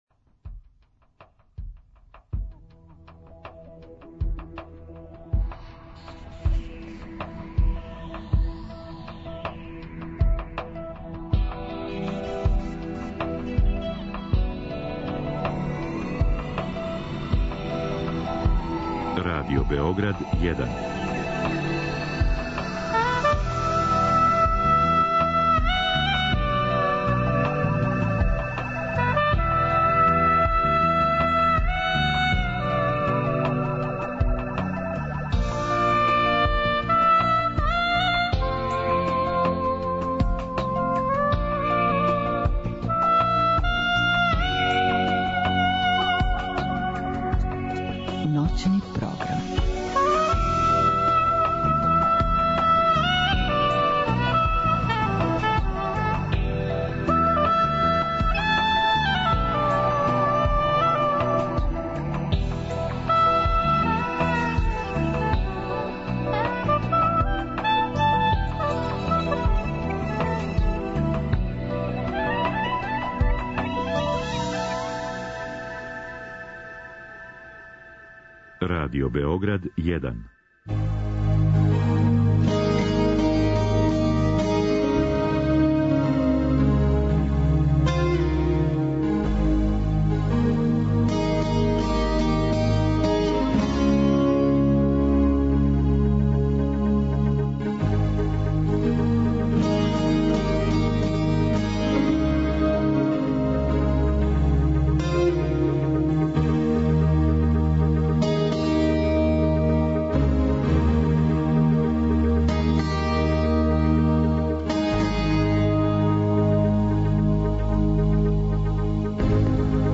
Ovom temom bavio se IMPRESSUM Druge strane računara - Noćnog programa Radio Beograda 1 emitovan 11.1.2014.godine oko 00.30h. Odmah na početku ovog teksta nalazi se i deo audio zapisa iz ove emisije, a zatim i sam tekst. Izvinjavam se zbog ne tako sjajnog kvaliteta audio snimka. Zbog veličine datoteke, audio zapis je nižeg kvaliteta.